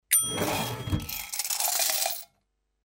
Apertura del compartimento del dinero de una caja registradora: campanita
caja registradora